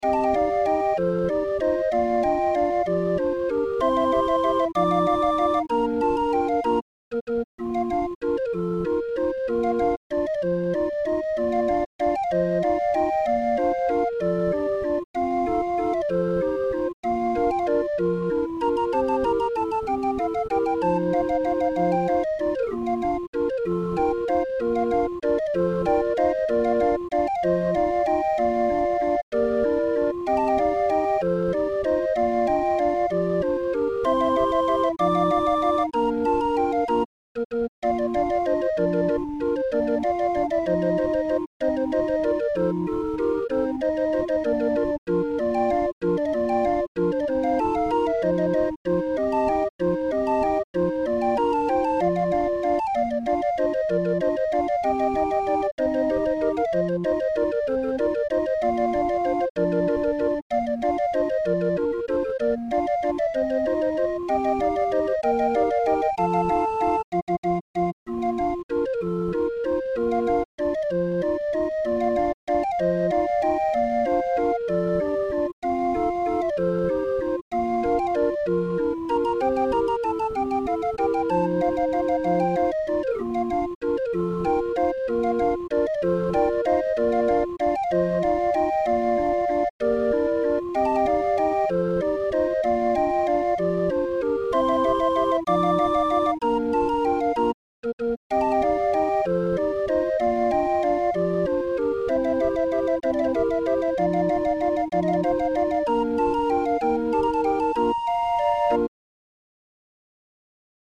Musikrolle 20-er